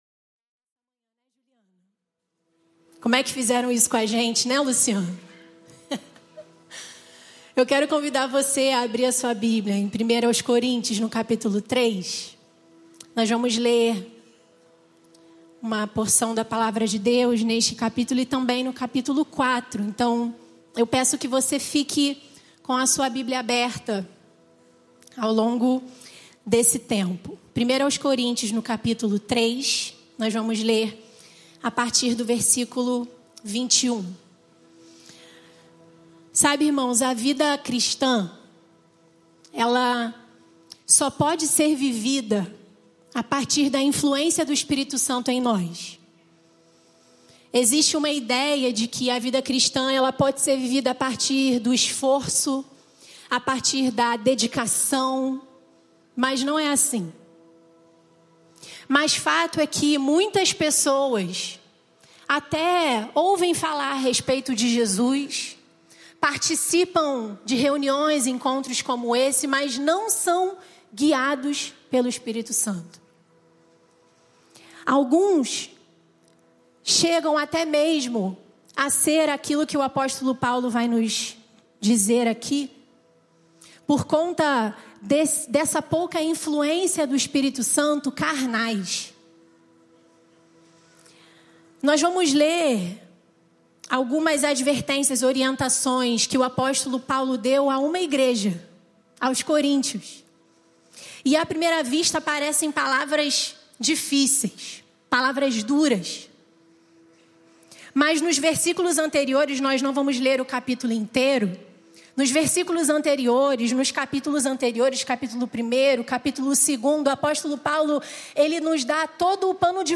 Igreja Batista do Recreio